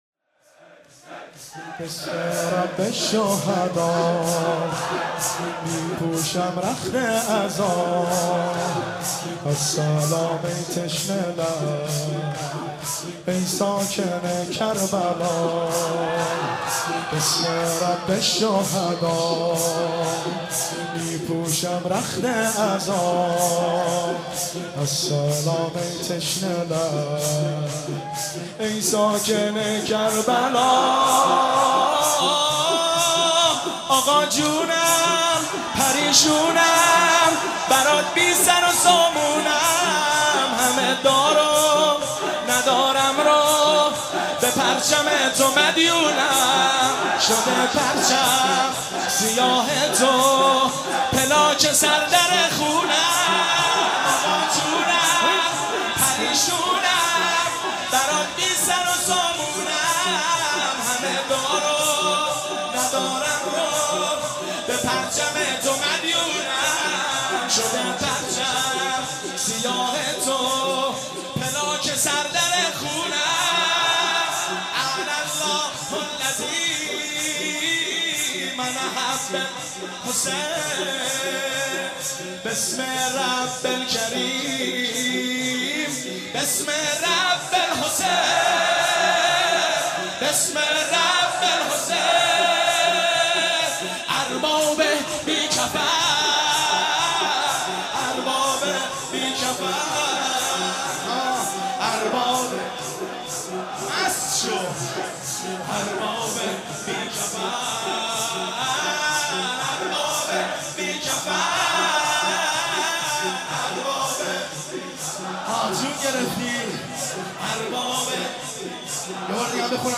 بسم رب الشهدا می پوشم رخت عزا | شور